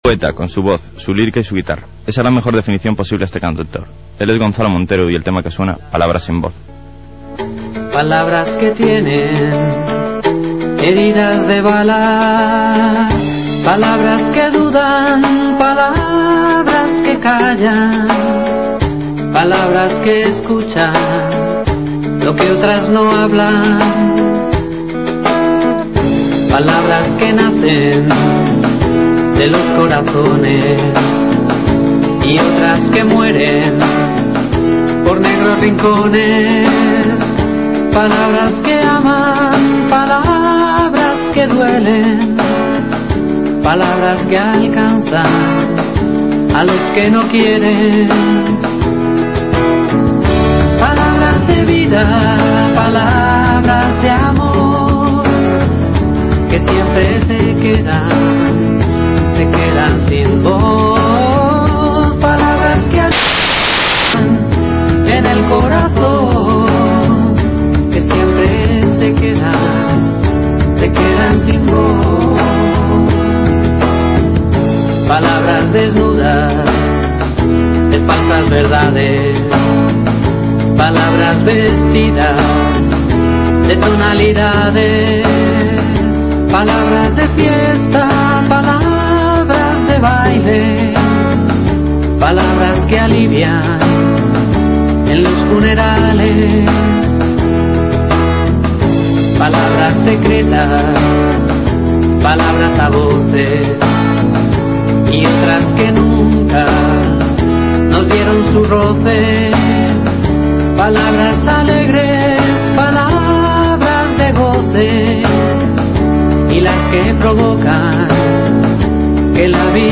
ENTREVISTA EN "EL RITMO DEL GARAJE" DE VILLAVICIOSA RADIO
Esta es la entrevista que me hicieron en el programa "El ritmo del garaje" de Villaviciosa Radio (107.5 FM) y que se emitió el pasado 19 de Abril de 2005.
1.- Presentación y canción "Palabras sin Voz" (3'49")